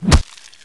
Звук удара стальной монтировкой по инопланетному созданию